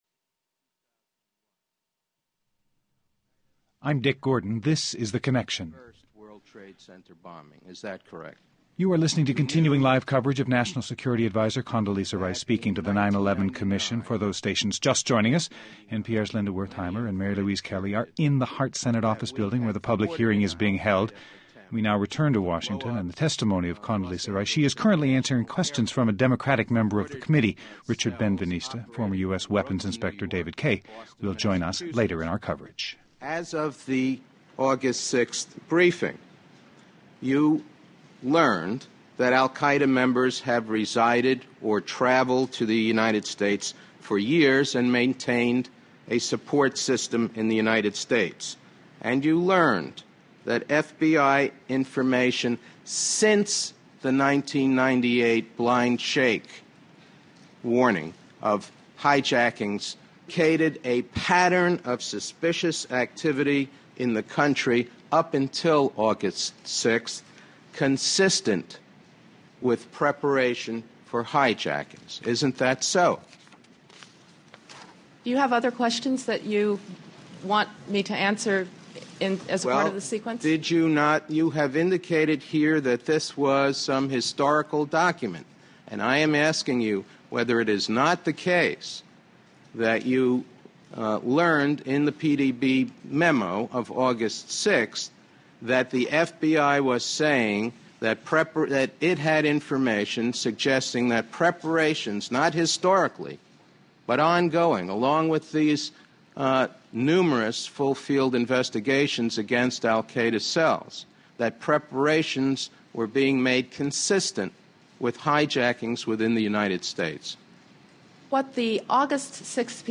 Condoleeza Rice goes before the 9-11 Commission. We bring you a two-hour special with live coverage of her testimony and analysis and reaction with former U.S. weapons inspector David Kay.